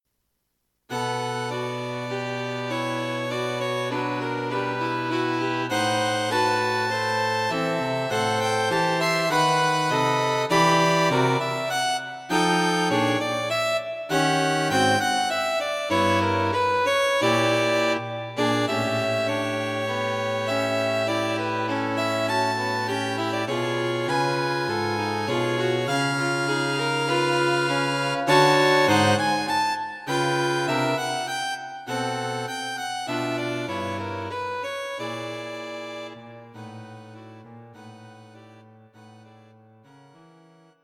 String Quartet for Concert performance